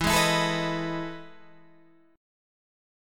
E Major 11th